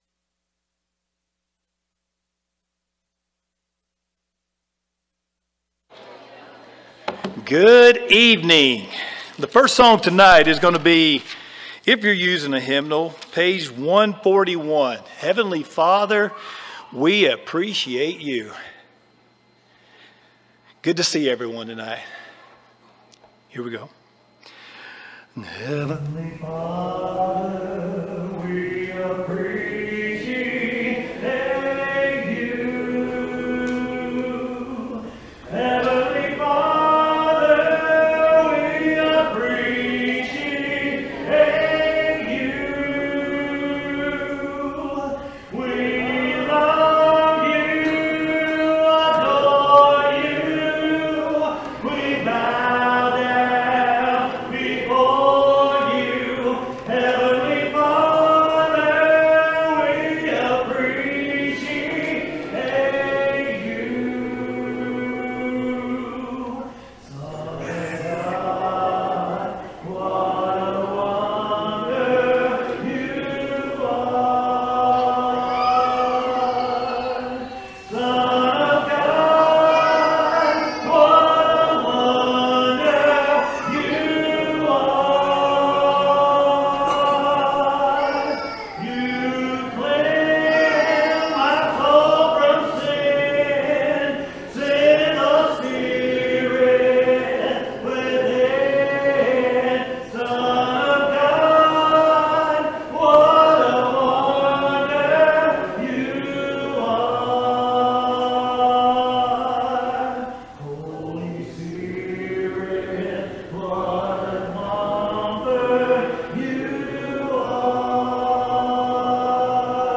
James 4:15, English Standard Version Series: Sunday PM Service